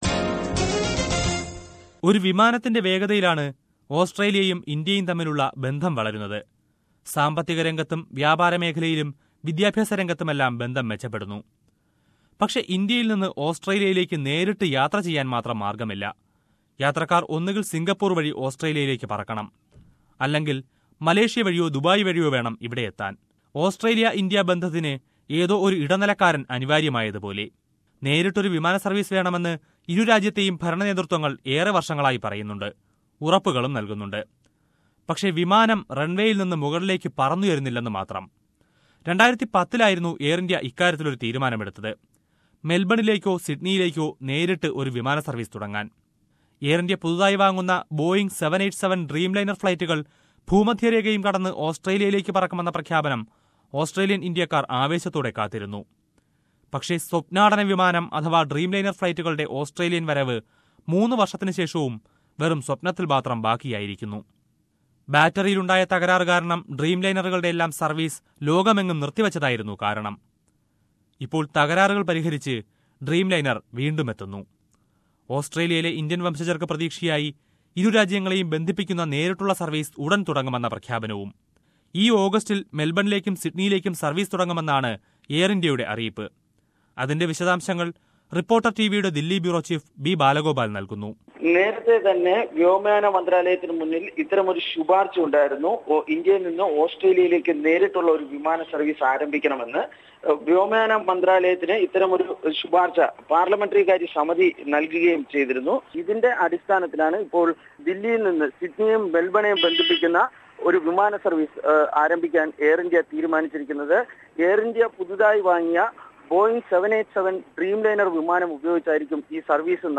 Air India announced direct service between New Delhi and Melbourne/Sydney from mid-August. A report on that...